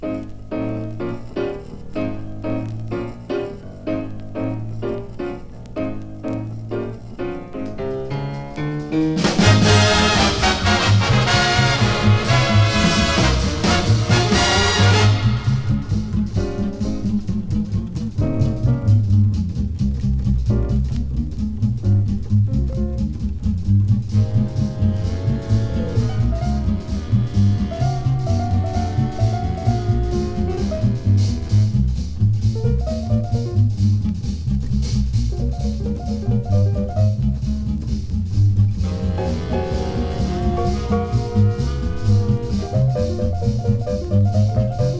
楽しく、ダイナミック、そして美しさもある1957年ニューヨーク録音。
Jazz, Swing, Big Band　France　12inchレコード　33rpm　Mono